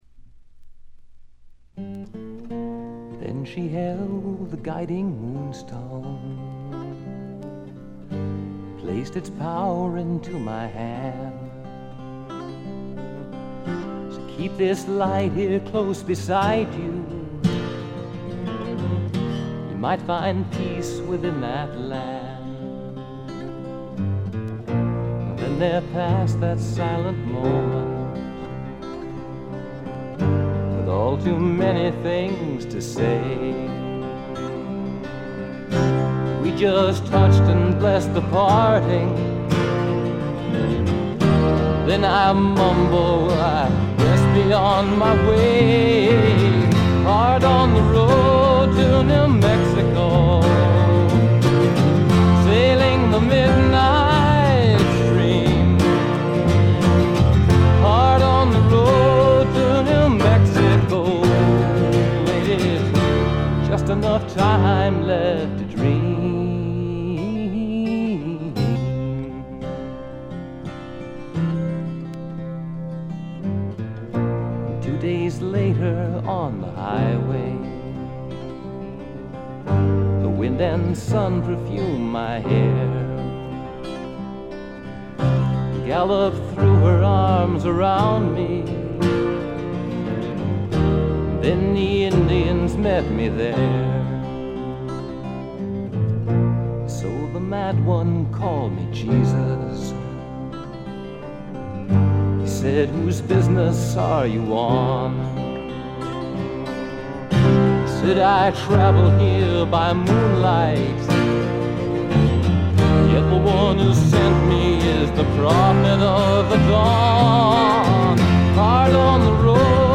ほとんどノイズ感無し。
全編がフォーキーなアウトローの歌の数々。
試聴曲は現品からの取り込み音源です。